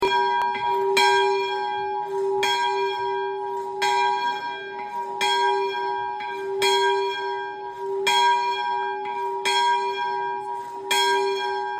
Le jeudi de l'Ascension 29 mai 2025 à 10H30 un office a eut lieu dans cette église
a fait sonner la cloche